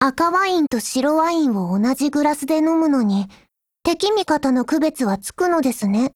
贡献 ） 协议：Copyright，其他分类： 分类:少女前线:史蒂文斯520 、 分类:语音 您不可以覆盖此文件。